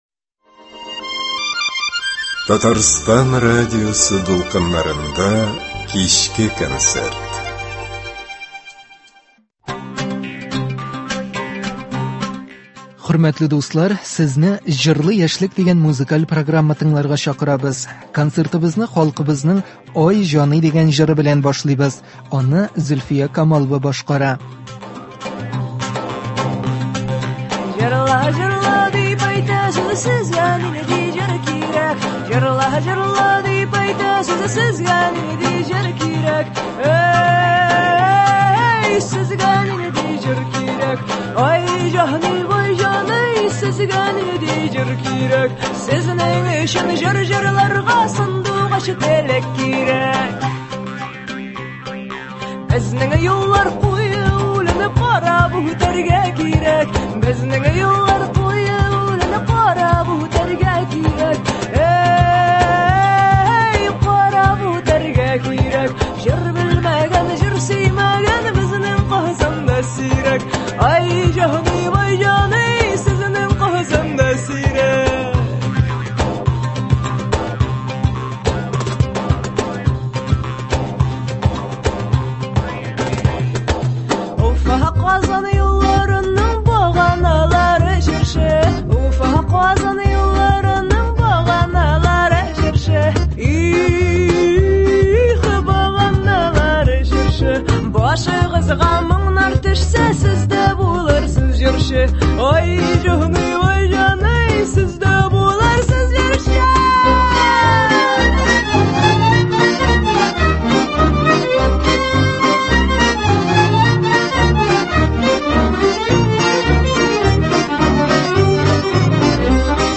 Яшь башкаручылар концерты.